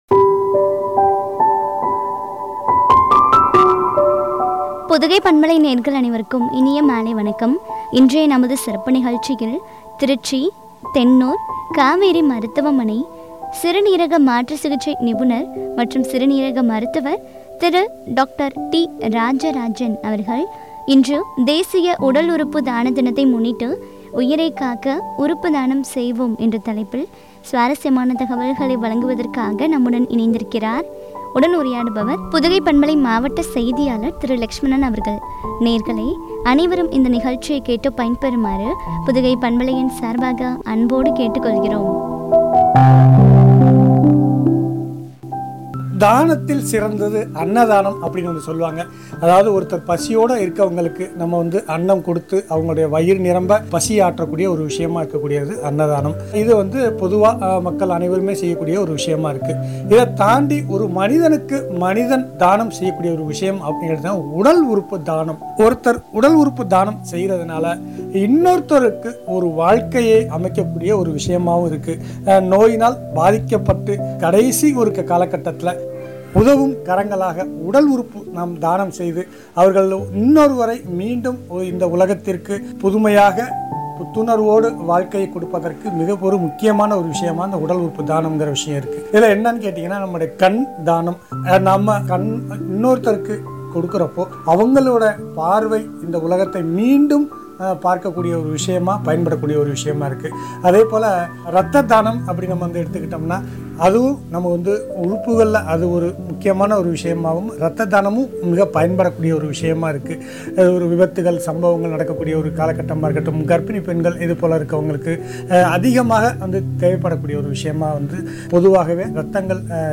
உரையாடல்.